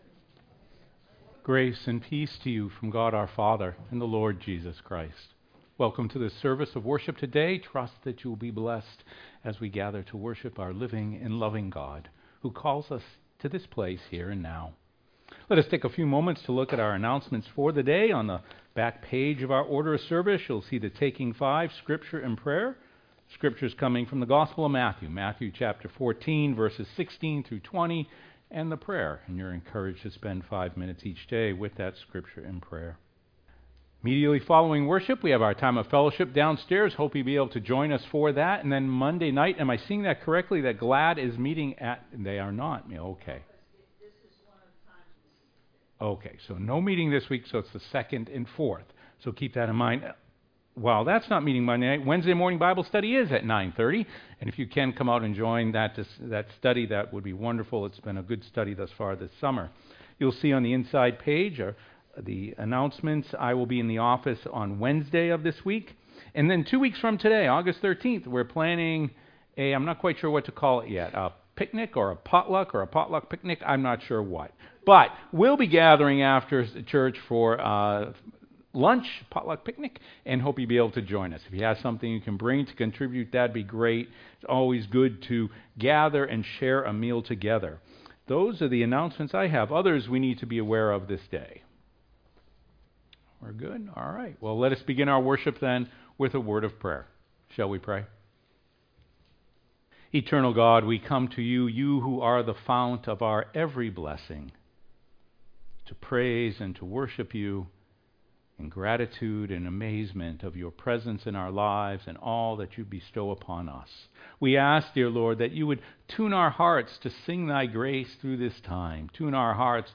sermon-3.mp3